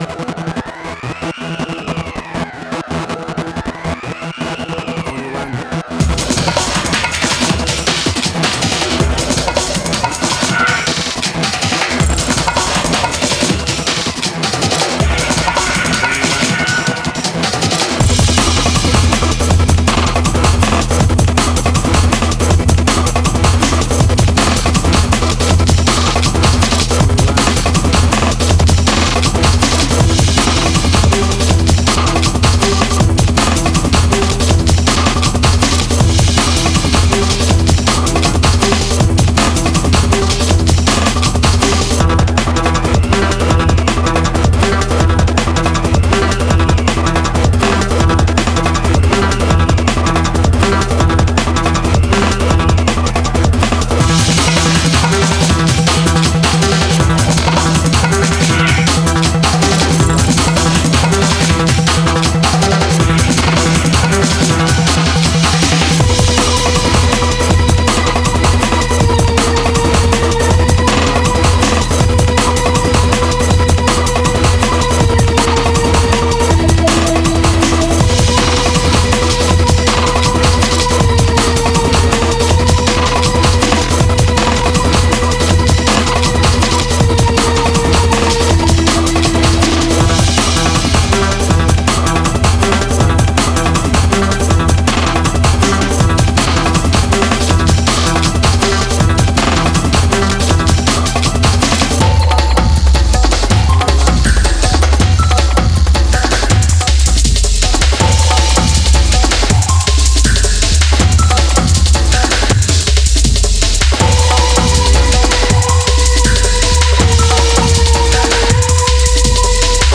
Drum n’ Bass Fusion.
Tempo (BPM): 160